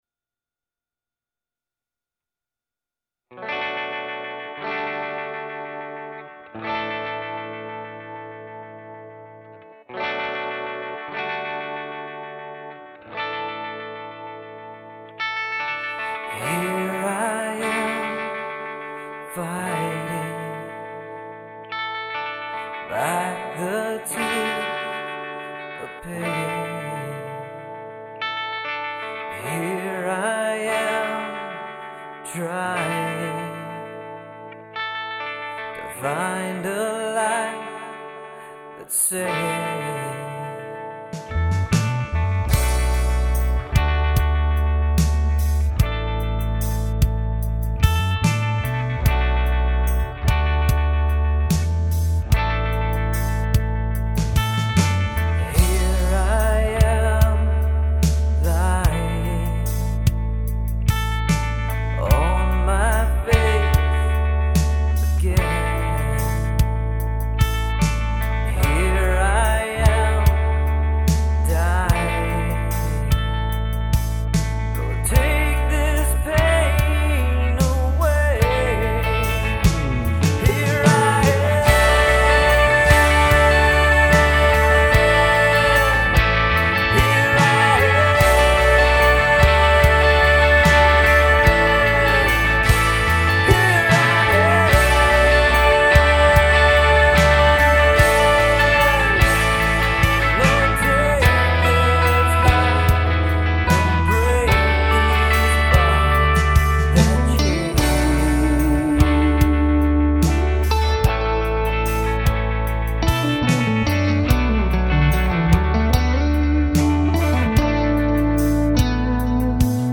Worship Rock band